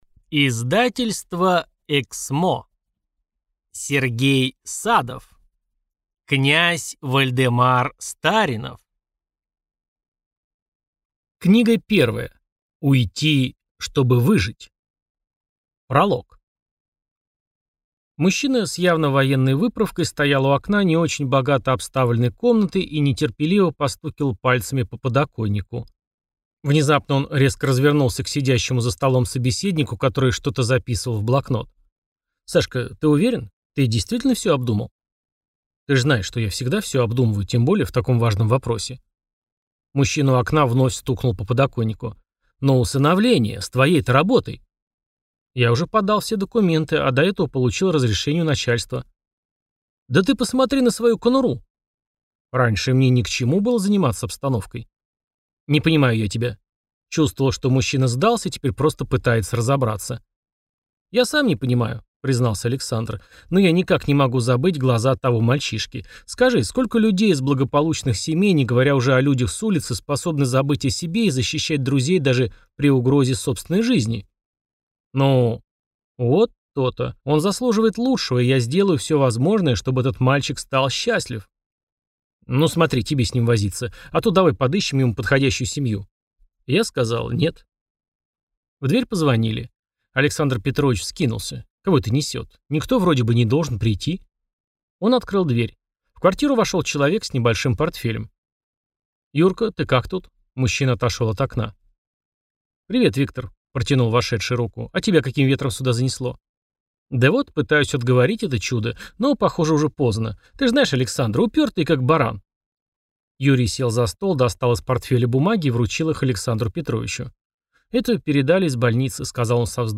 Аудиокнига Уйти, чтобы выжить | Библиотека аудиокниг